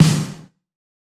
pcp_snare02.wav